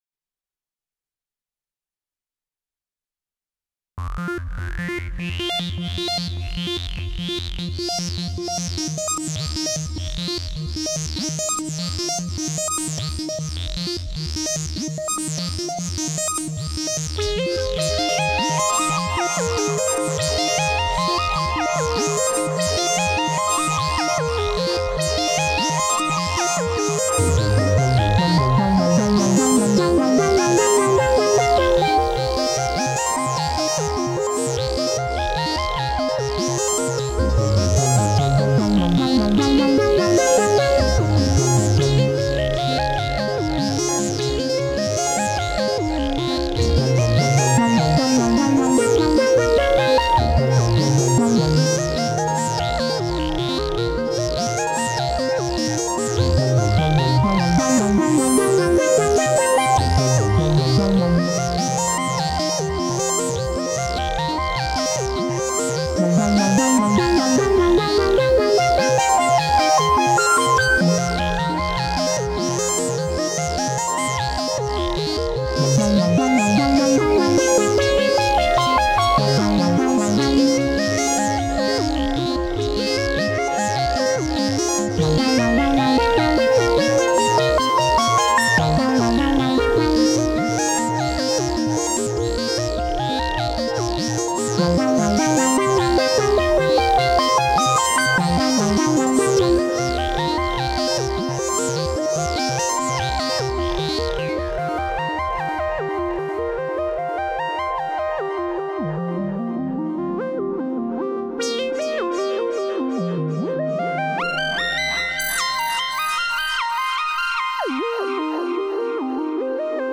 The thing I like the most about SY Chip is the selection of animated waveforms, so I focused there!
I’m using the animated waveforms for all three tracks and only track 3 has offsets enabled.
Tracks 9 and 11 are using LP shelf filters with envelopes.
I sort of like how the analog tracks break when they go too high.